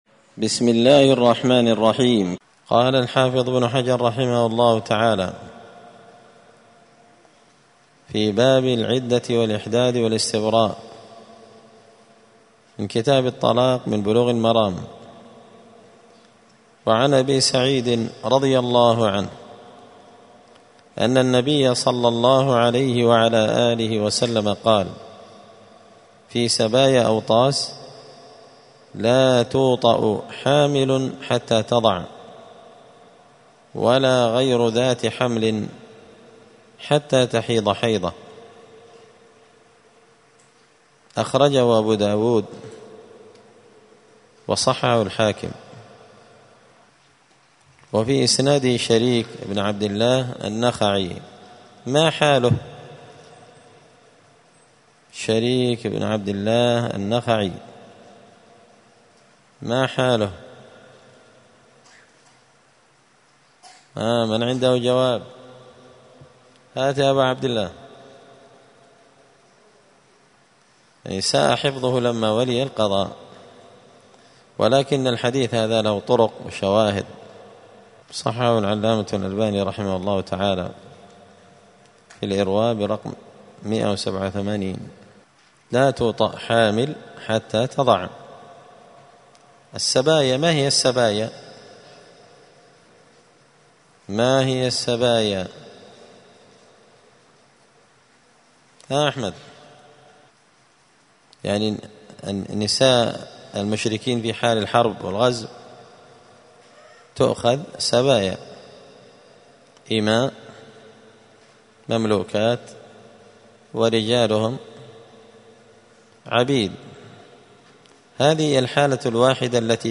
*الدرس الثامن والعشرون (28) {تابع لباب العدة الإحداد والاستبراء}*